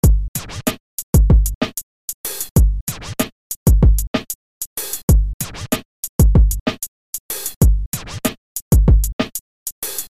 Rap - RnB
Hip hop